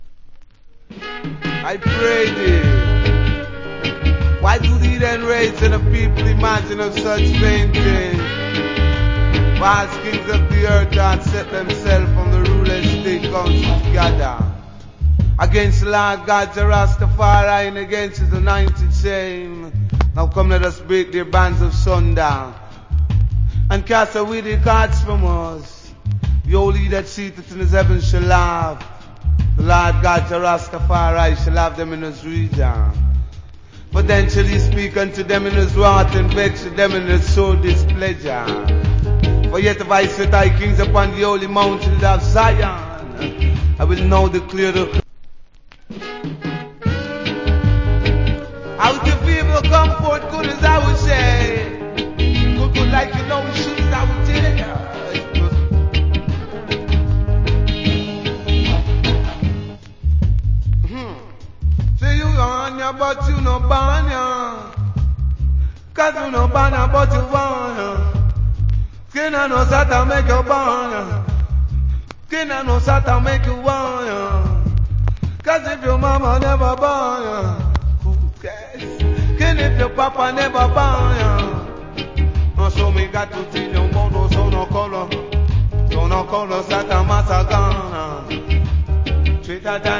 Good DJ.